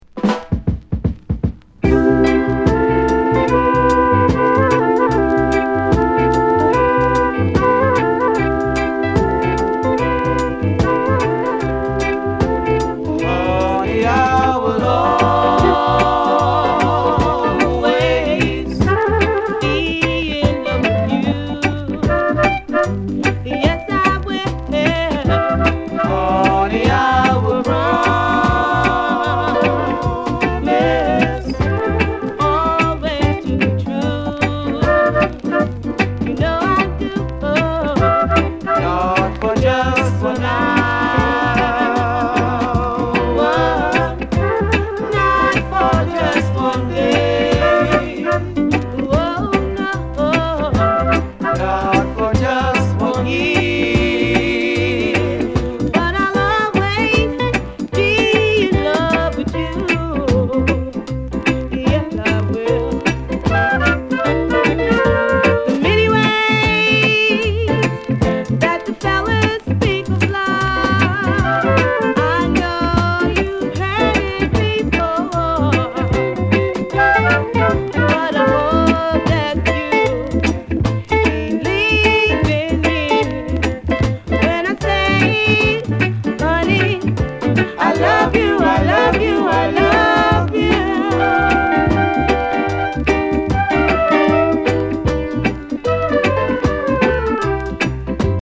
LP]ロックステディーレゲエ